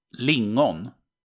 Vaccinium vitis-idaea is most commonly known in English as 'lingonberry' or 'cowberry'.[13][14][15] The name 'lingonberry' originates from the Swedish name lingon ([ˈlɪŋɔn]
Sv-lingon.ogg.mp3